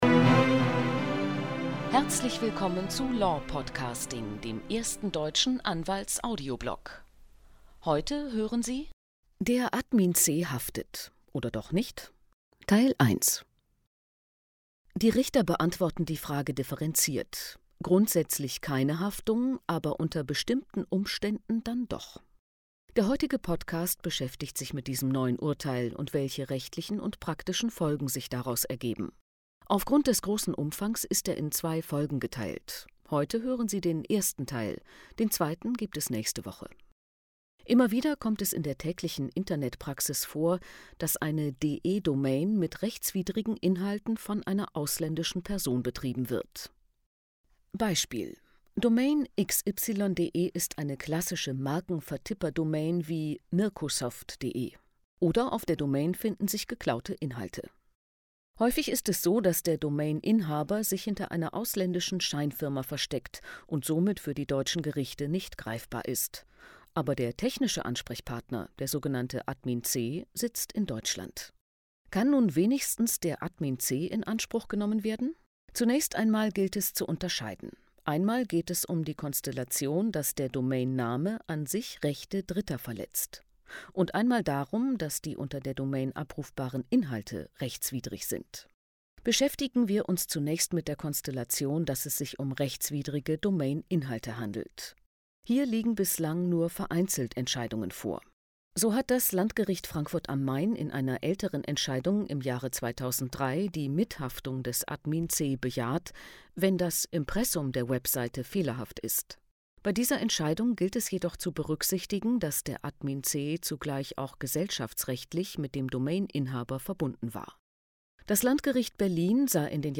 P.S.: Aufgrund einer unerwarteten Raum-Zeit-Anomalie hat ein Schwarzes Loch die ersten paar Sätze des Podcasts leider verschluckt.